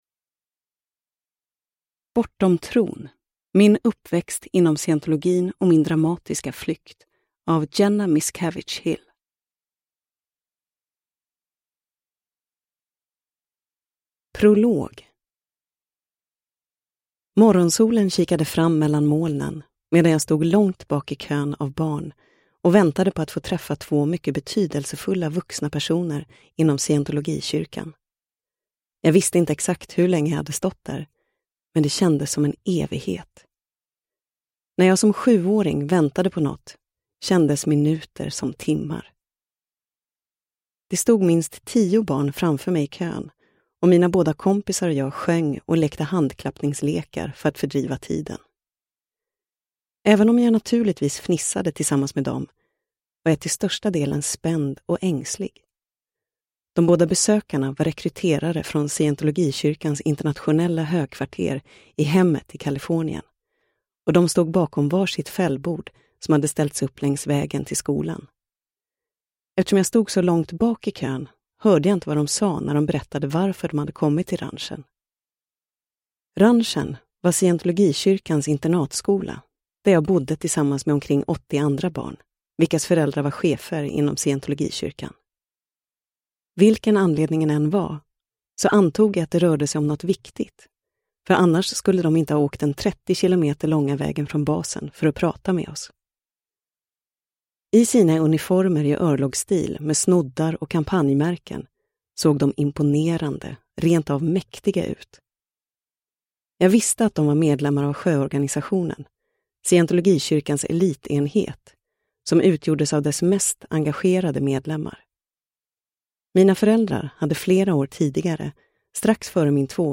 Bortom tron : min uppväxt inom scientologin och min dramatiska flykt – Ljudbok – Laddas ner